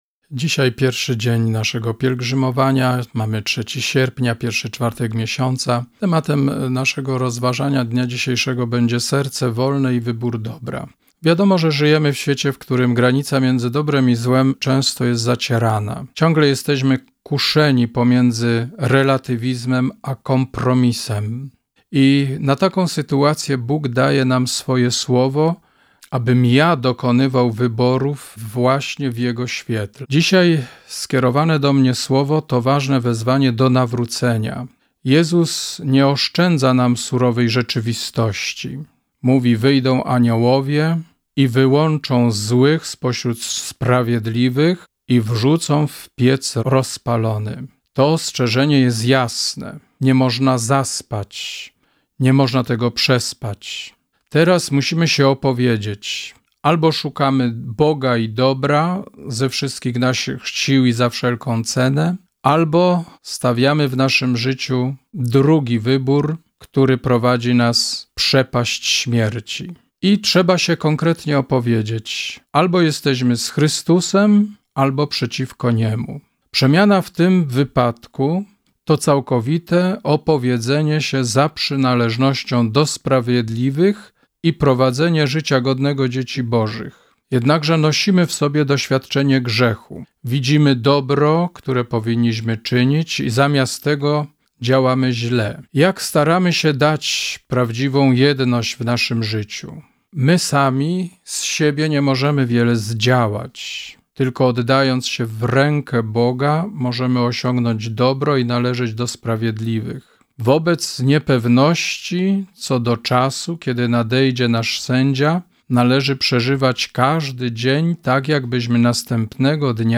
#1 Rozważania Pielgrzymkowe￼